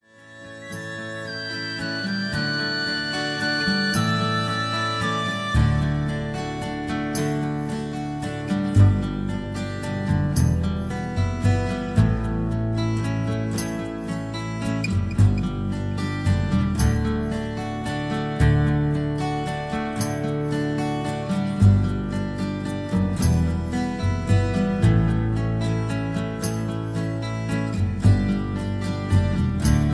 Tags: backing tracks , irish songs , karaoke , sound tracks